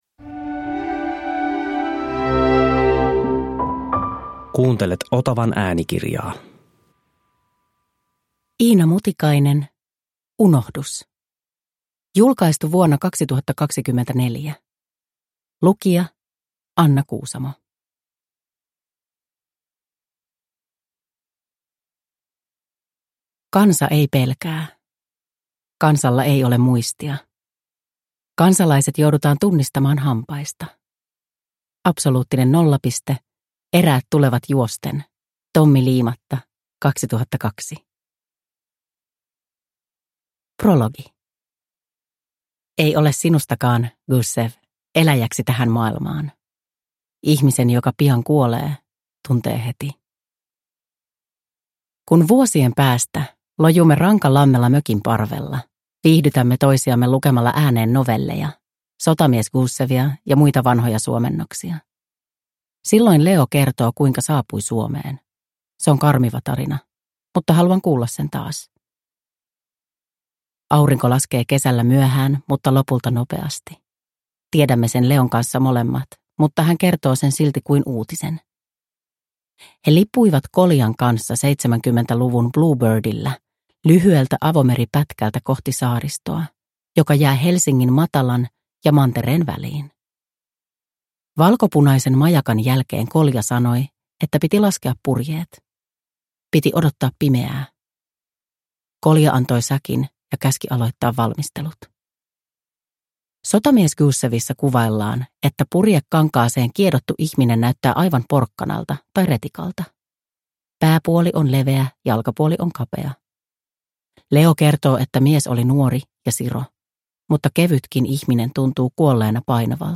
Unohdus – Ljudbok